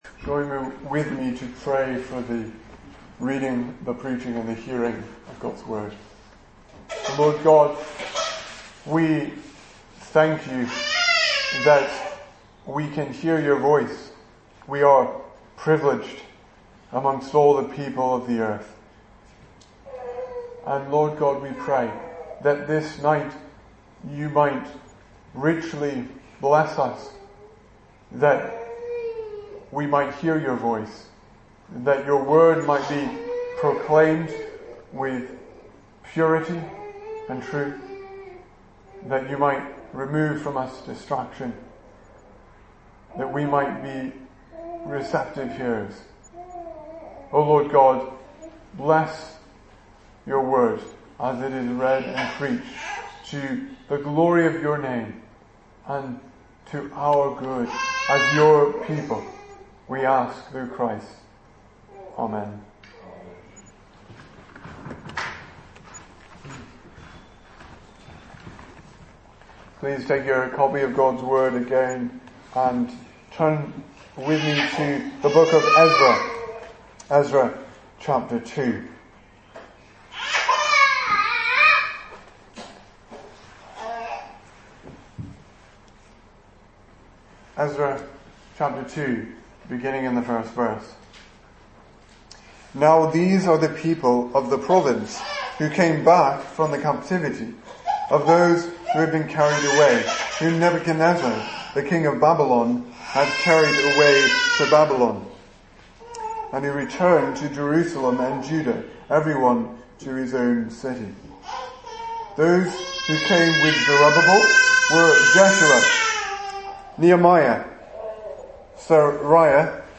2018 Service Type: Sunday Evening Speaker